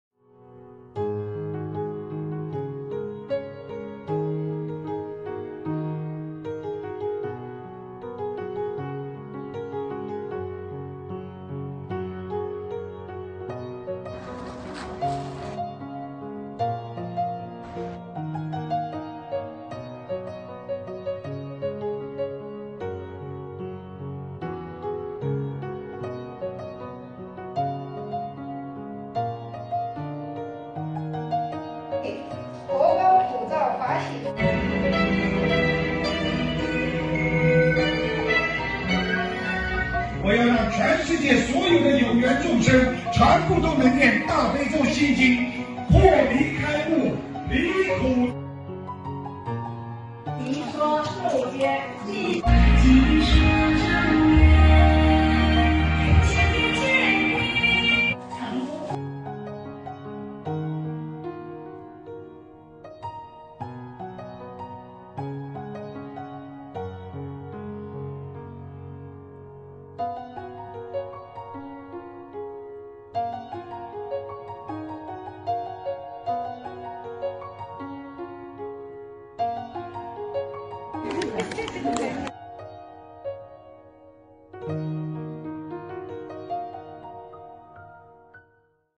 音频：硅谷Sunnyville素餐视频会！